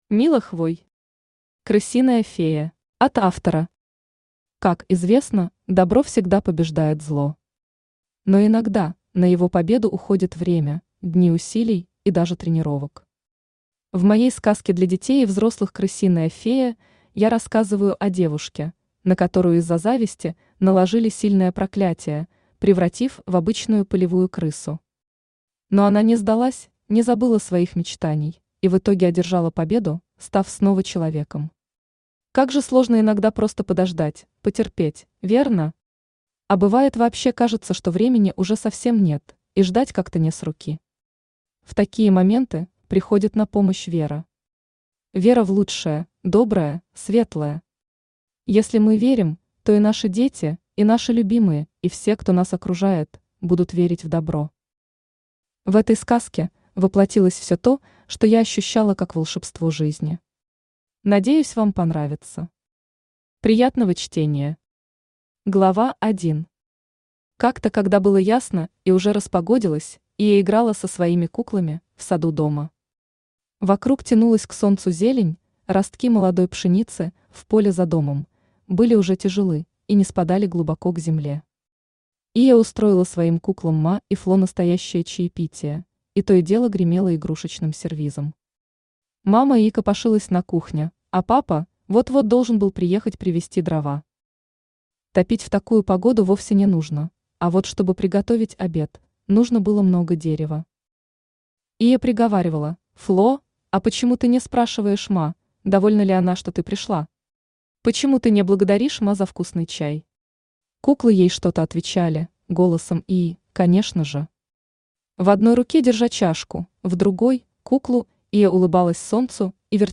Аудиокнига Крысиная фея | Библиотека аудиокниг
Aудиокнига Крысиная фея Автор Мила Хвой Читает аудиокнигу Авточтец ЛитРес.